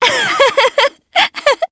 Yanne-Vox_Happy3_jp.wav